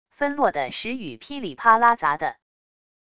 纷落的石雨噼里啪啦砸地wav音频